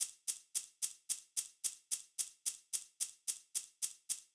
35 Cabassa.wav